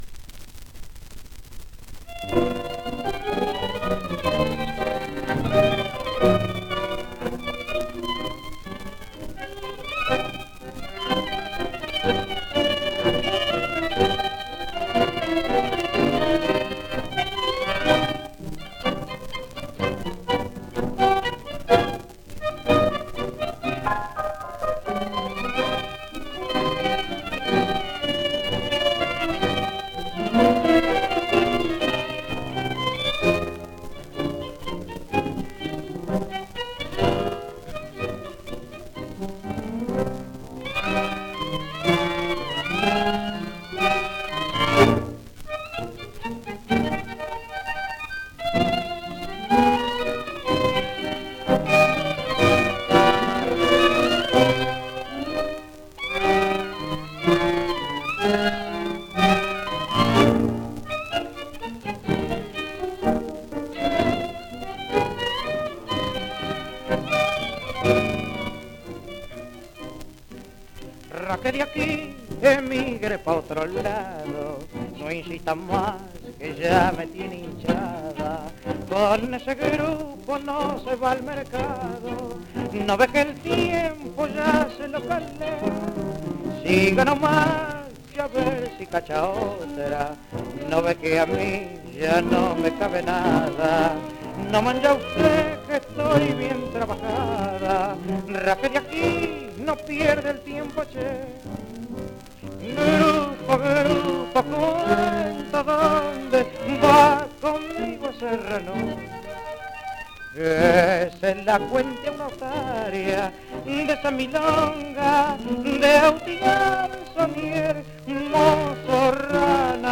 1 disco : 78 rpm ; 25 cm Intérprete